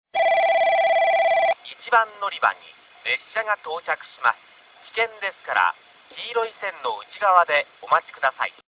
☆旧放送
1番のりば接近放送　男声